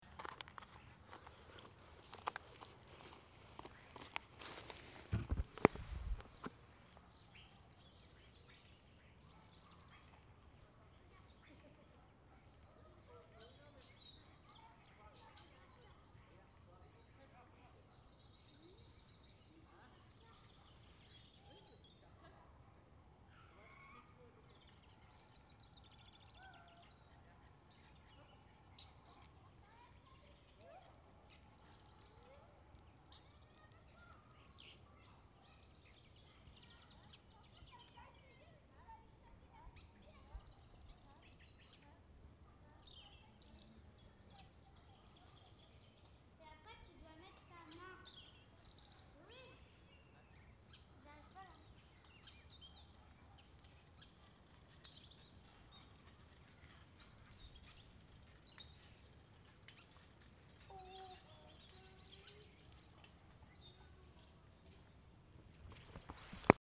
09/02/2015 14:00 Nous prenons le bac pour traverser la Loire et passer l’après-midi le long du canal de Lamartinière. Le temps est splendide, le froid et le soleil viennent piquer nos pommettes, les oiseaux chantent.